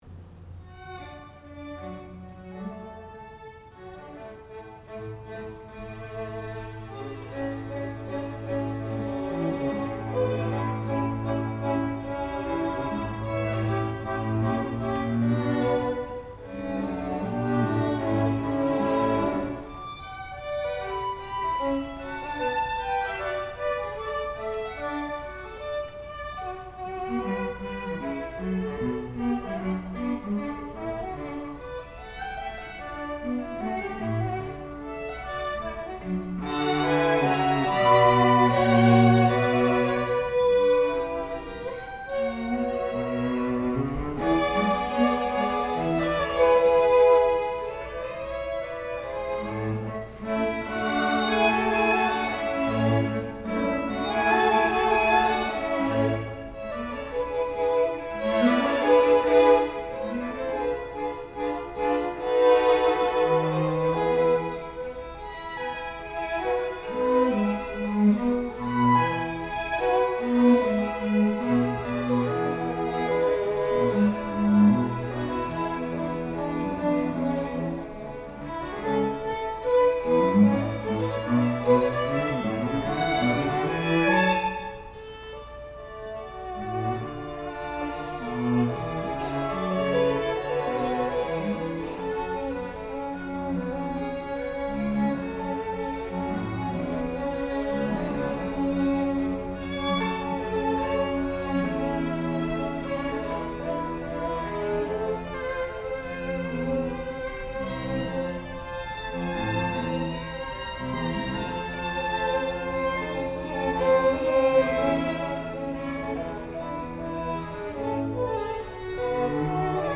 Chamber Groups